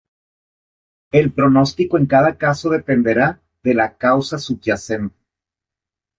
sub‧ya‧cen‧te
/subʝaˈθente/